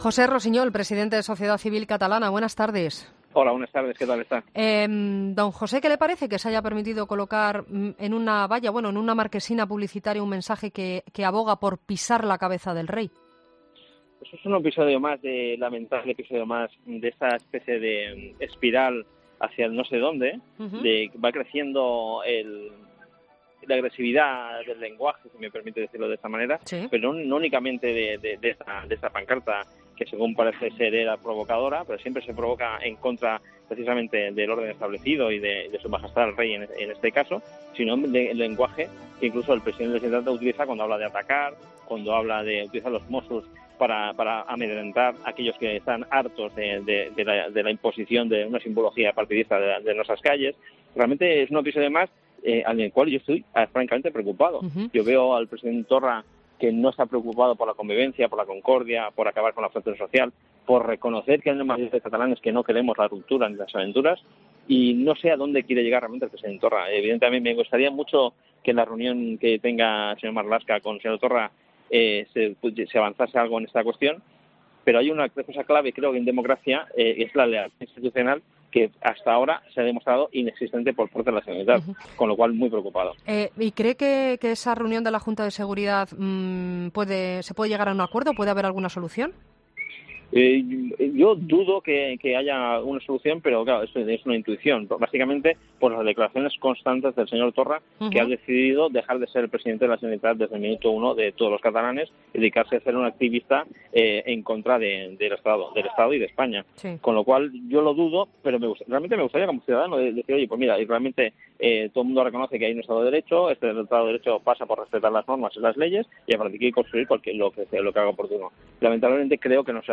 Entrevistas en La Linterna
Con Ángel Expósito